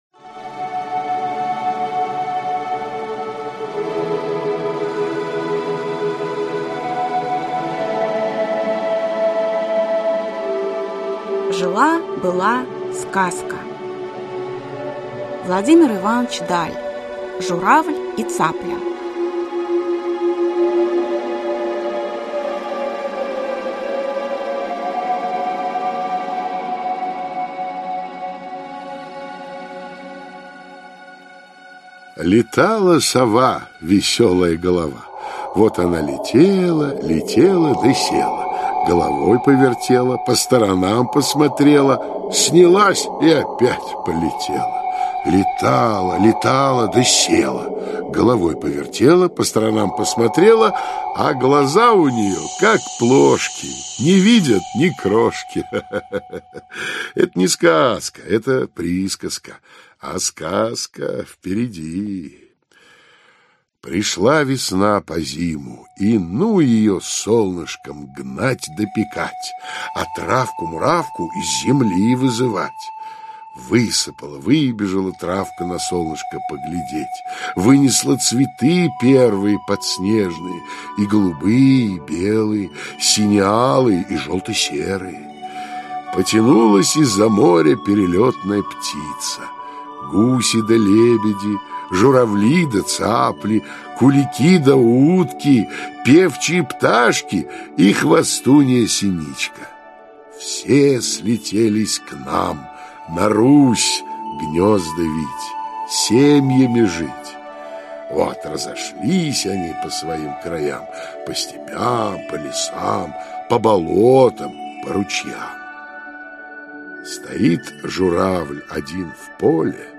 Аудиокнига Сказки в аудиоспектаклях | Библиотека аудиокниг